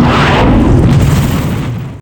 rocket_shoot_crit.wav